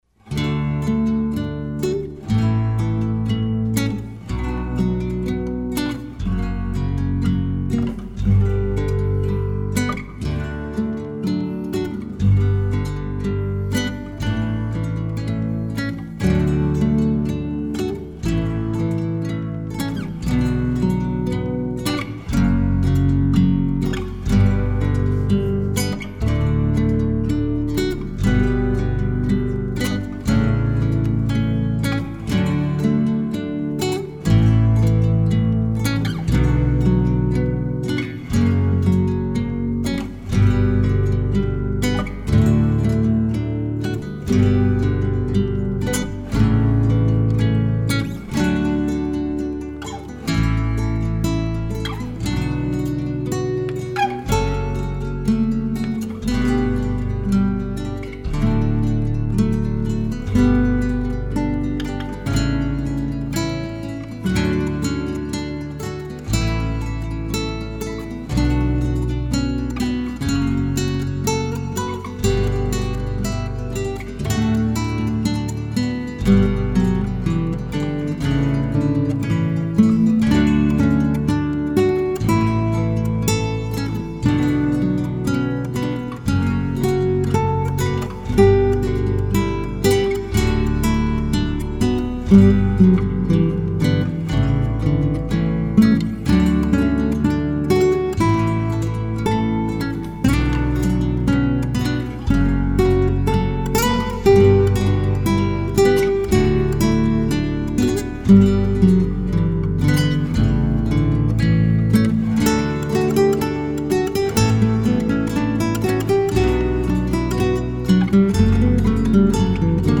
Canon-Acoustic-ggt.mp3